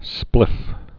(splĭf)